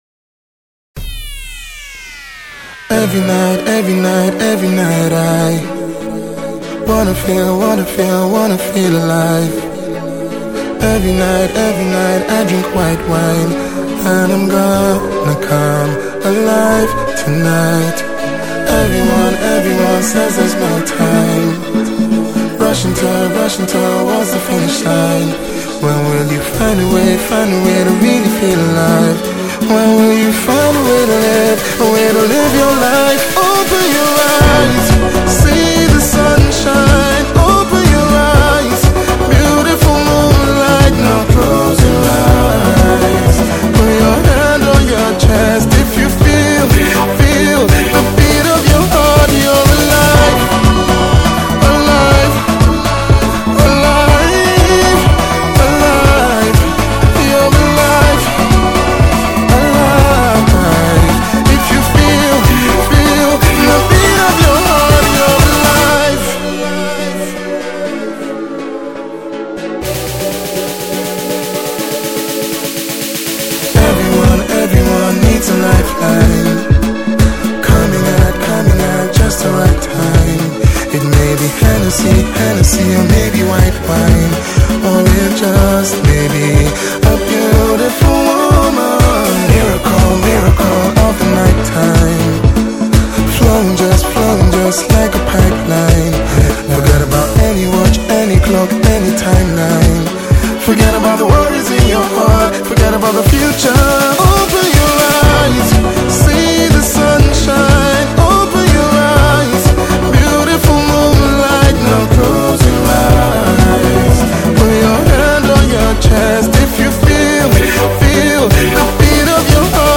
took a very electro-house/dance approach
familiar harmonic style and traditional touch
calmer, relaxed, lower tempo feel